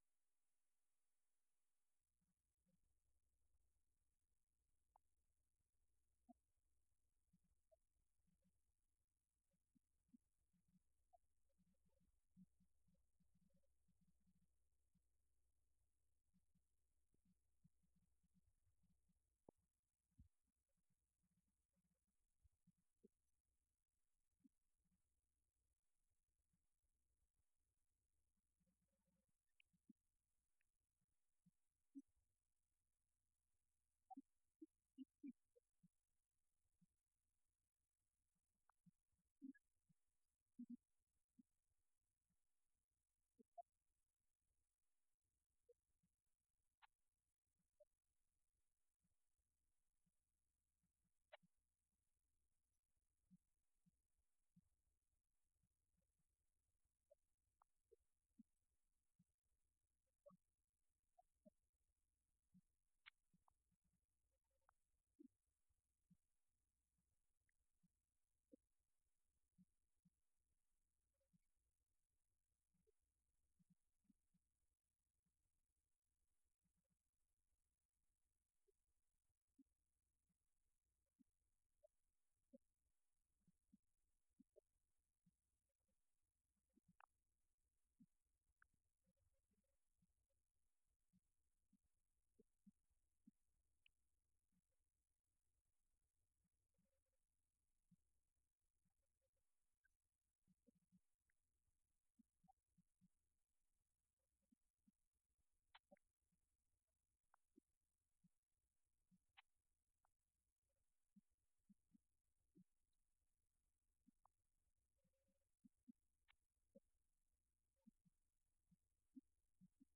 Event: 6th Annual Southwest Spiritual Growth Workshop
this lecture